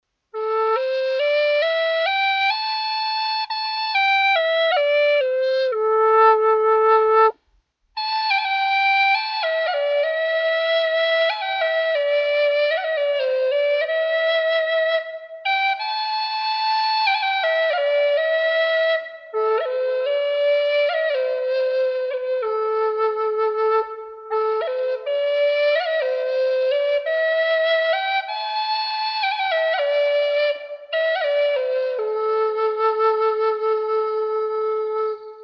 Western Cedar Native American Flutes
This is one of my favorite woods to make Native American Flutes out of because it is the most "mellow" sounding of all! 5 Hole Western Cedar Flues are also great Native American Flutes for beginners because the wood is so "forgiving" of the playing styles of new flute players.
$165 Key of A